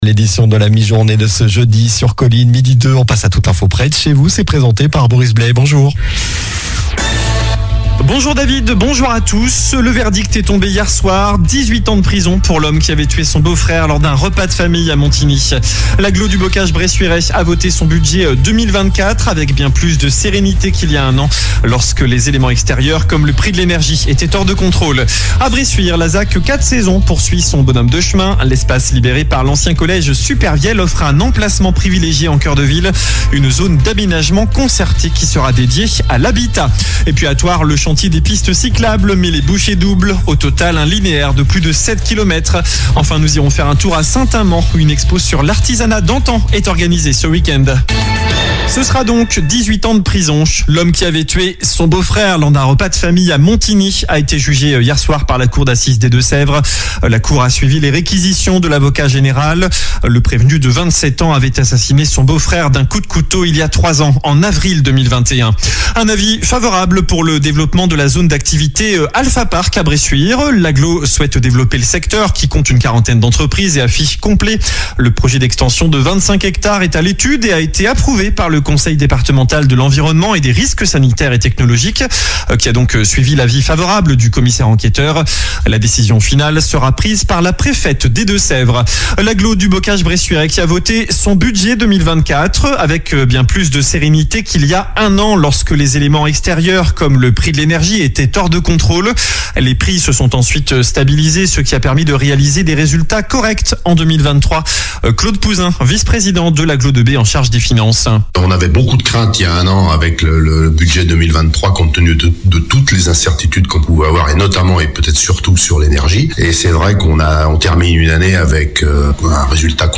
Journal du Jeudi 21 mars (midi)